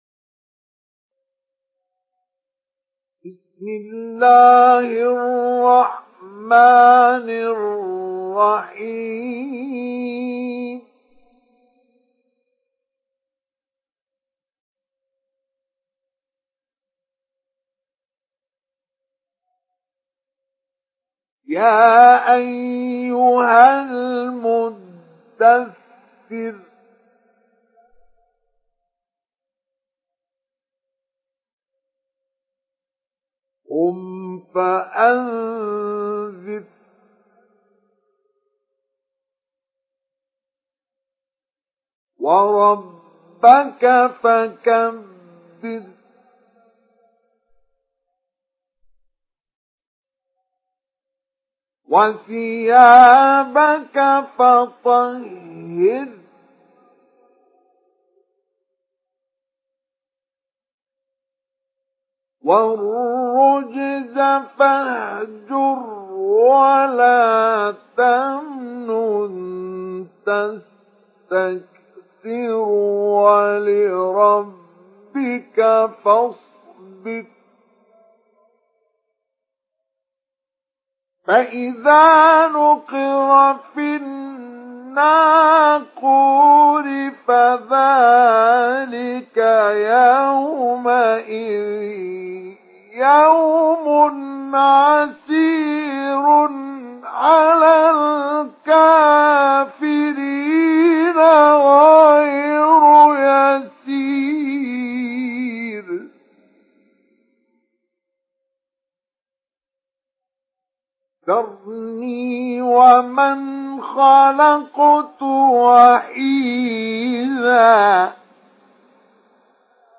Surah আল-মুদ্দাস্‌সির MP3 in the Voice of Mustafa Ismail Mujawwad in Hafs Narration
Surah আল-মুদ্দাস্‌সির MP3 by Mustafa Ismail Mujawwad in Hafs An Asim narration.